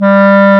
Index of /90_sSampleCDs/Roland L-CDX-03 Disk 1/CMB_Wind Sects 1/CMB_Wind Sect 6
WND CLAR G3.wav